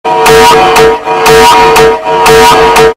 Italian Eas Alarm (MOCK)
Simulazione dell'allarme Eas italiano
italian-eas-alarm-mock.mp3